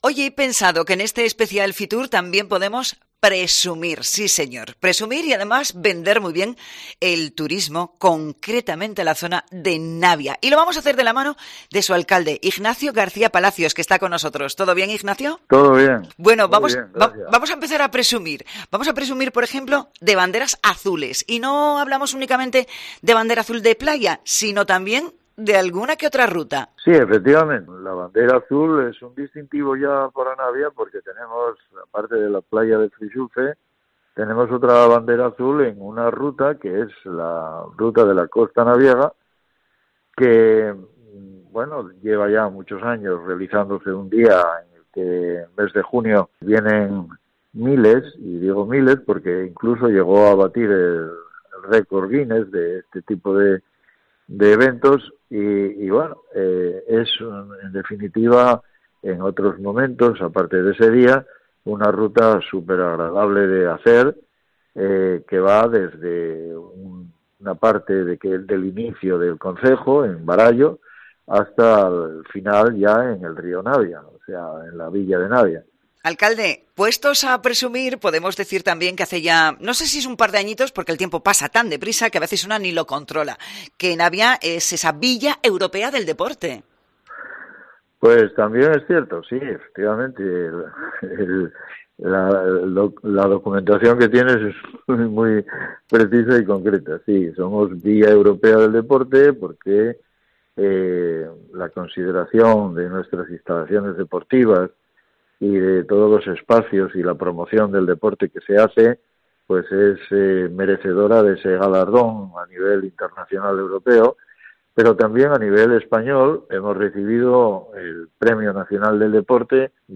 El alcalde del concejo occidental asturiano, Ignacio García Palacios, ha pasado por el especial de COPE en la Feria Internacional de Turismo desde IFEMA Madrid
Fitur 2022: Entrevista al alcalde de Navia, Ignacio García Palacios